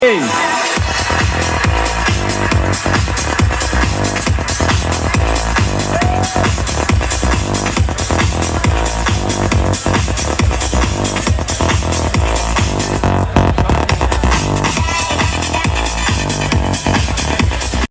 nice electro!!
I can only hear the track as a choppy stream (not continuously)